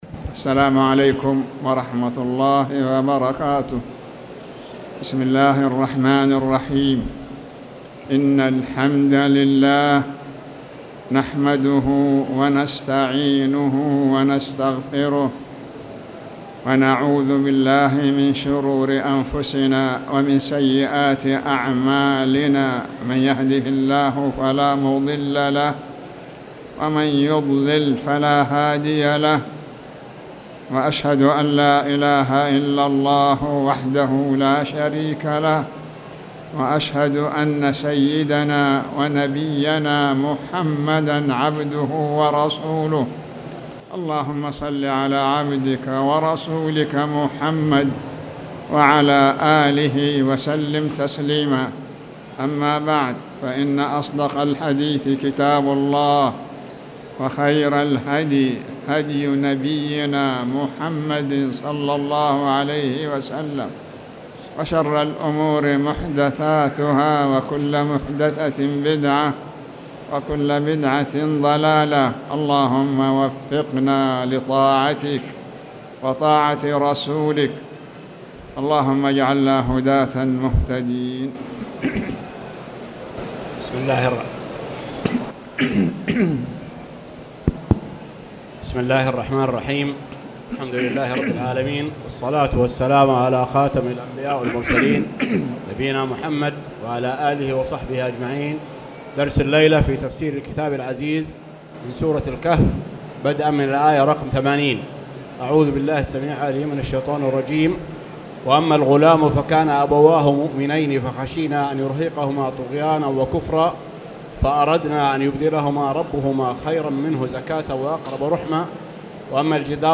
تاريخ النشر ٩ صفر ١٤٣٨ هـ المكان: المسجد الحرام الشيخ